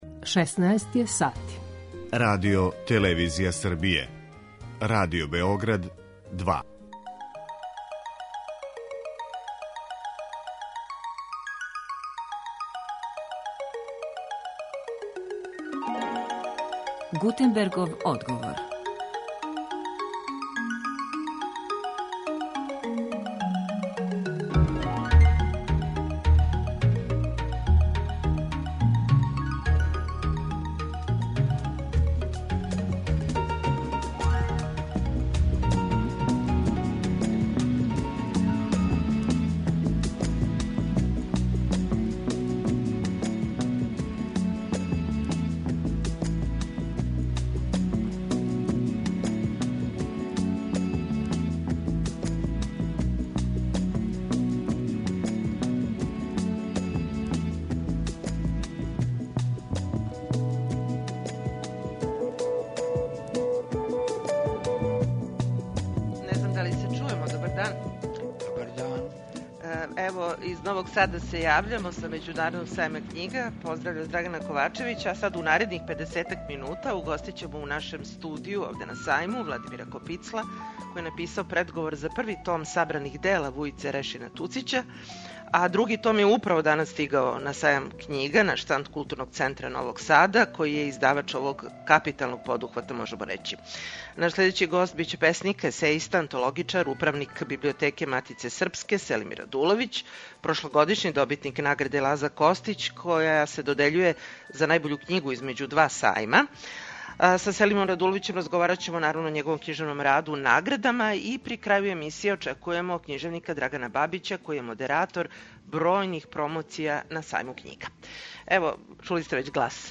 Емисија се реализује са Сајма књига у Новом Саду.